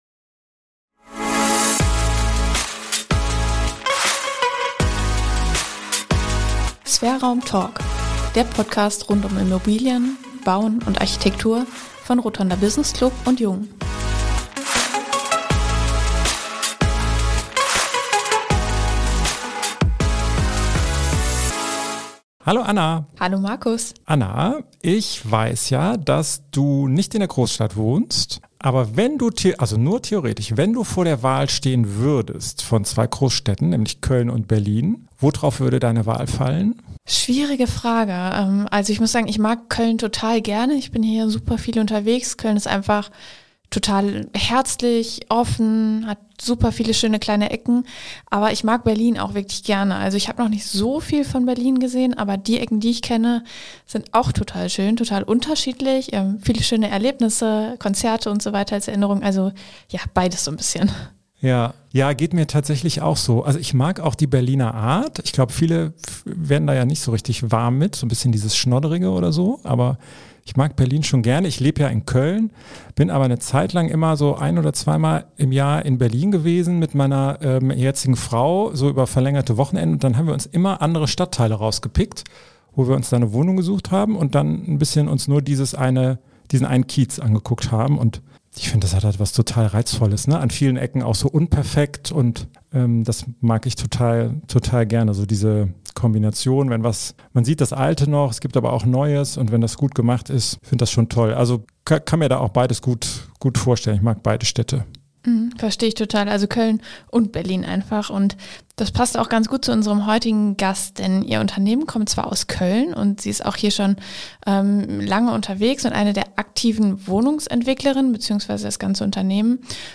Ein vollgepacktes Gespräch